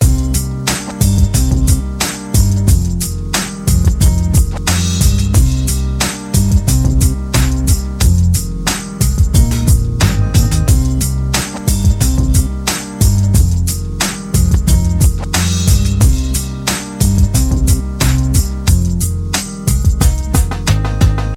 Category 🎵 Music